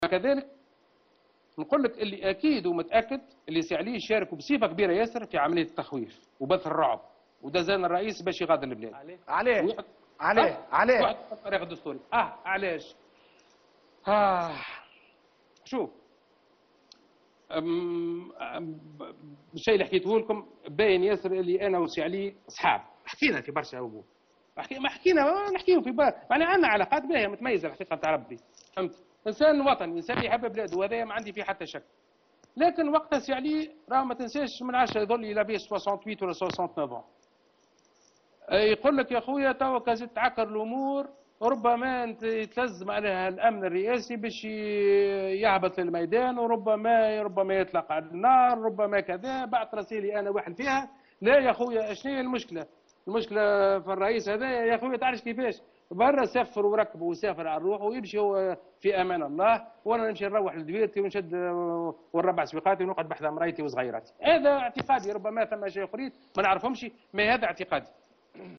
Belhassen Trabelsi, beau-frère de l'ex président Ben Ali, a déclaré ce lundi 9 janvier 2017 lors d'une interview accordée à la chaine télévisée Attessia TV, que Ali Seriati, ancien directeur de la sécurité présidentielle a participé à l’opération ayant pour objectif de faire peur à la famille présidentielle durant la période de la révolution et la pousser à quitter le pays.